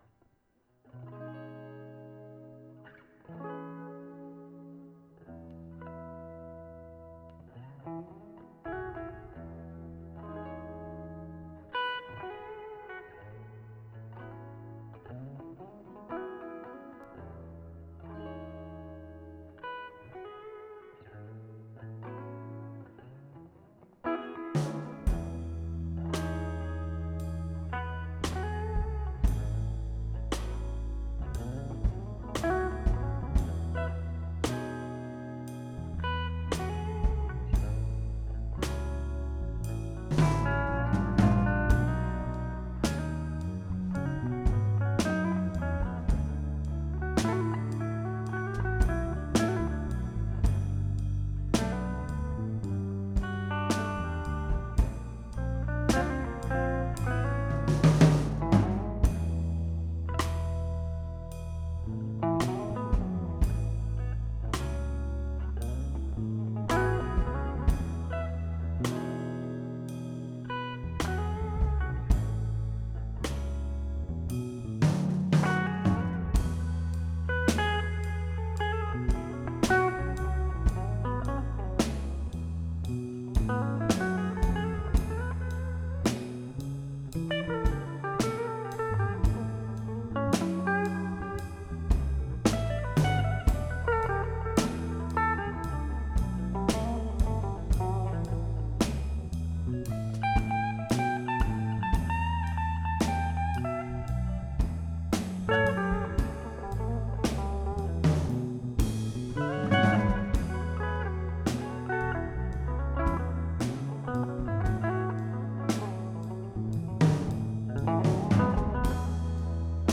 The goal for this recording was to create a cohesive piece that was smooth and worked on reverb and delay to make it sound similar to the recording room. After recording on the Avid C24 I took this piece and edited it with Protools and used the Midas M32R to mix automations in real time.